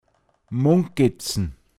Wortlisten - Pinzgauer Mundart Lexikon
munkeln, Gerüchte verbreiten mungezn